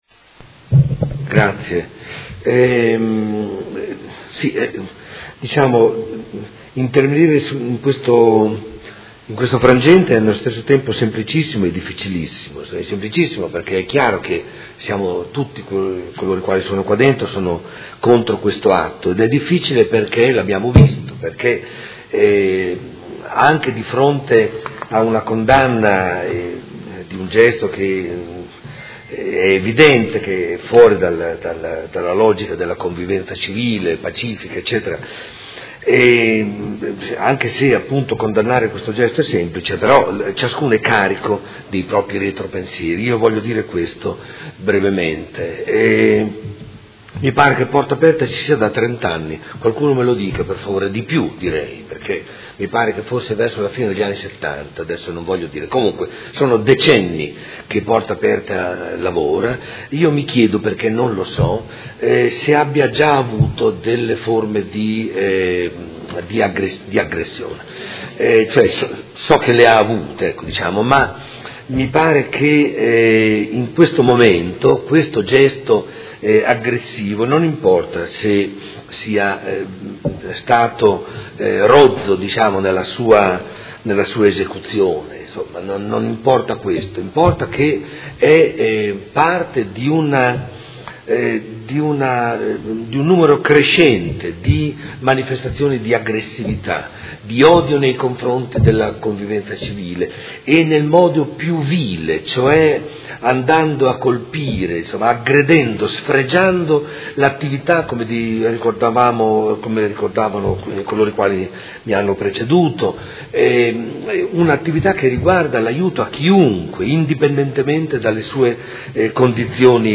Seduta del 20/07/2017 Odg 112553: Condanna atto vandalico a Porta Aperta